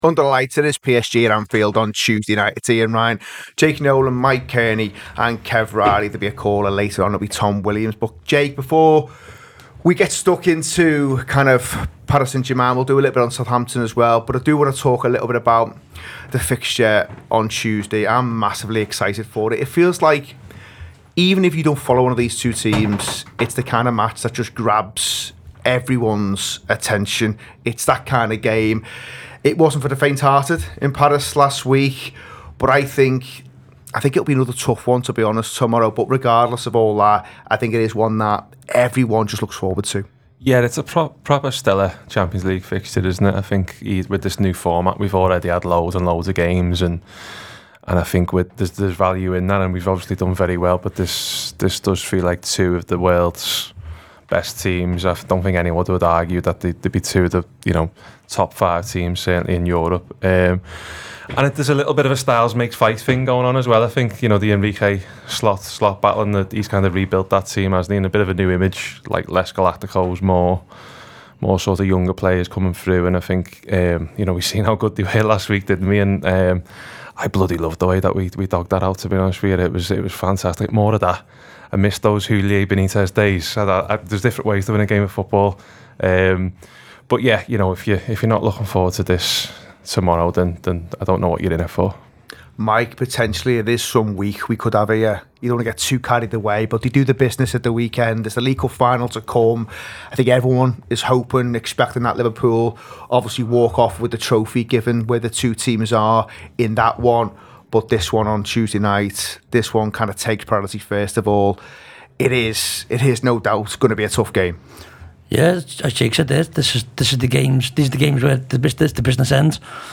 Below is a clip from the show – subscribe for more on Liverpool v Paris Saint-Germain in the Champions League…